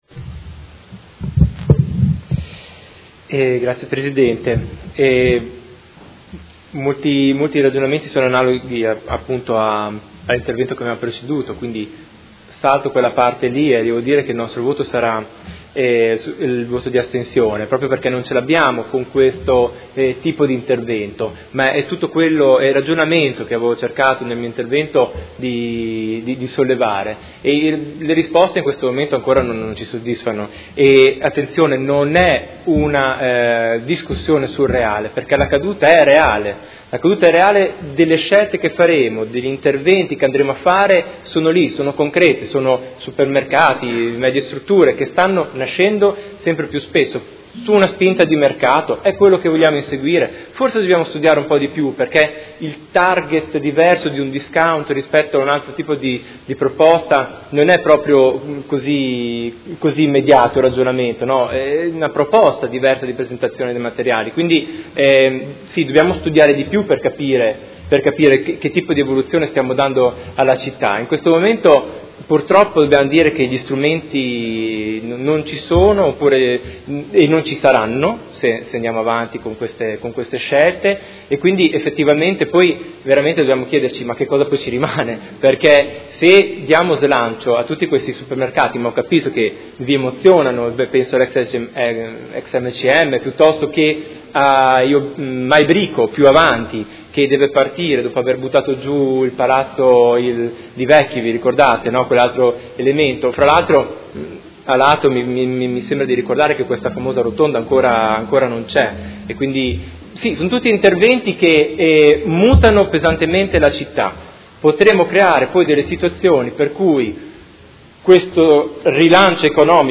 Seduta del 06/04/2017 Dichiarazioni di voto.